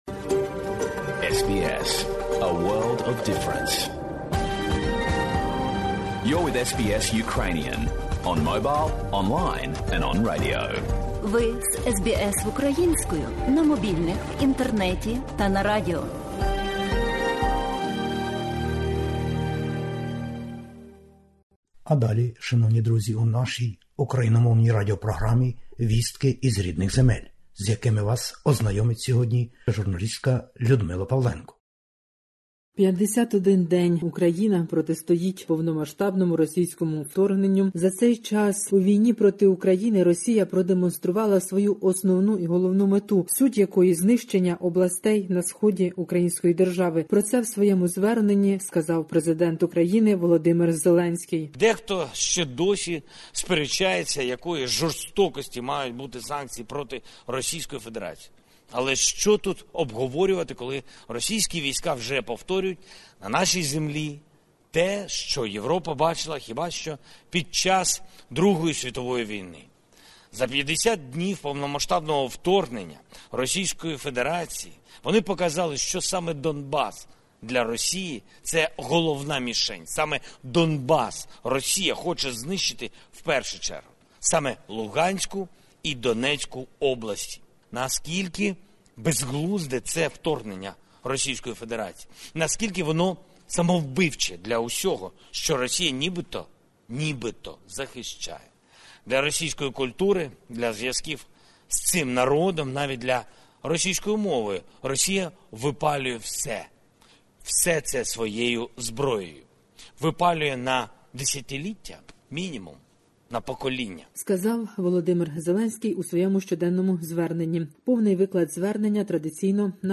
Добірка новин із воюючої України.